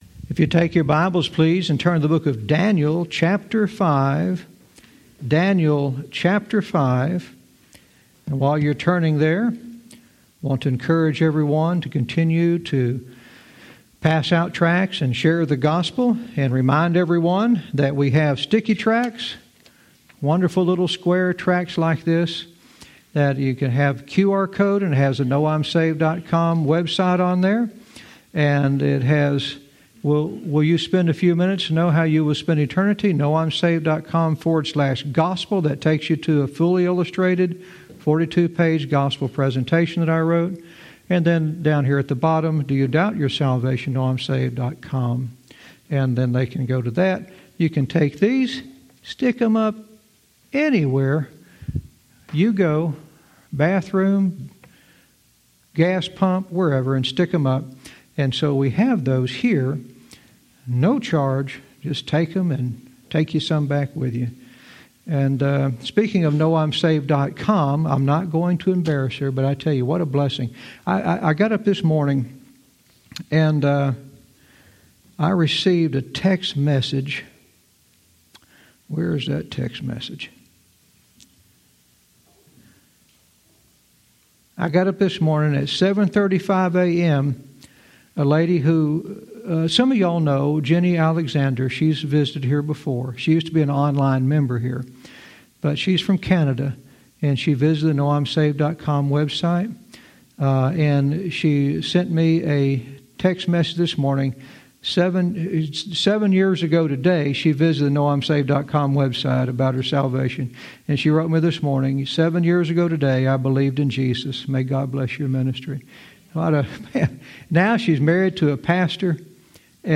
Verse by verse teaching - Daniel 5:16-17 "All Knotted Up"